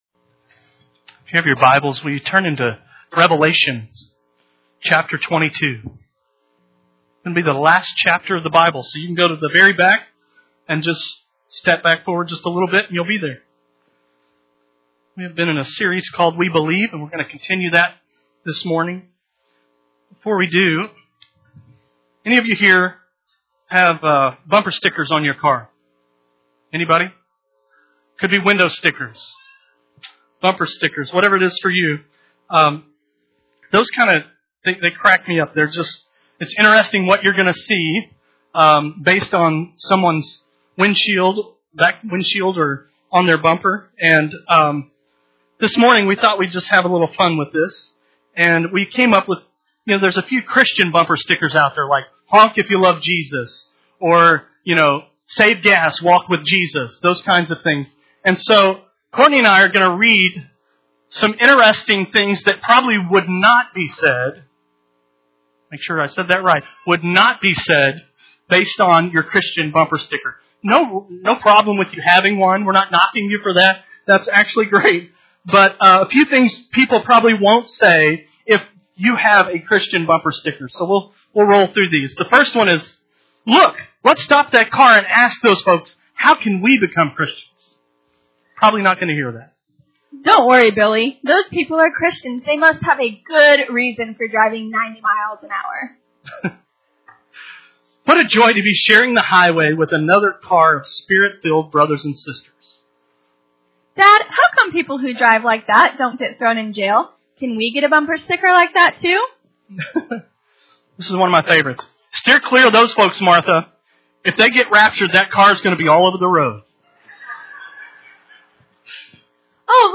Sunday Morning Service
Sermon